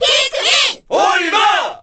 Olimar_Cheer_Korean_SSBB.ogg.mp3